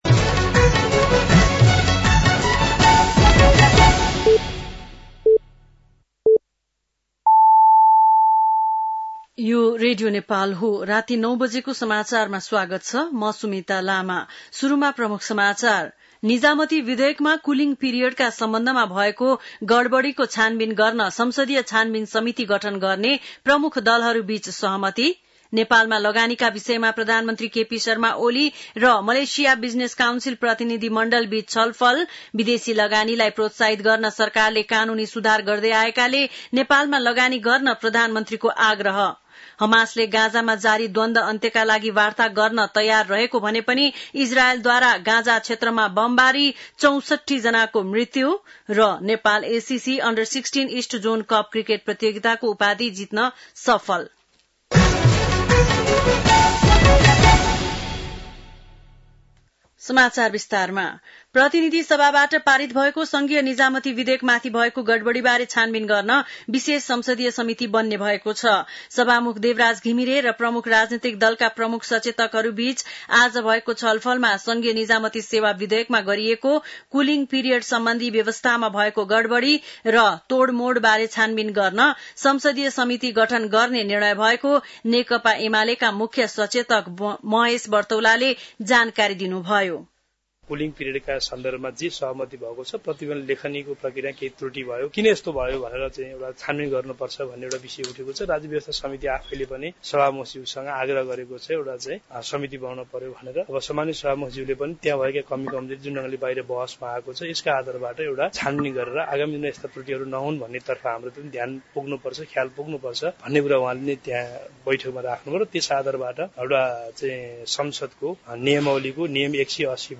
बेलुकी ९ बजेको नेपाली समाचार : २१ असार , २०८२
9-pm-nepali-news-.mp3